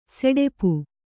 ♪ seḍepu